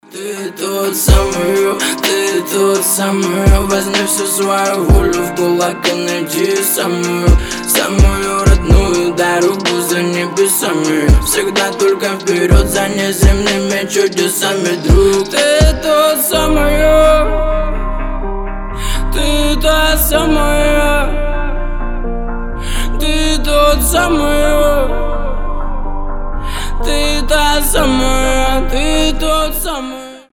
лирика
спокойные